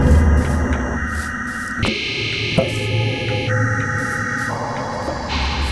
Random noise sounds
This random noise sound happens expectationally, no idea what it triggers.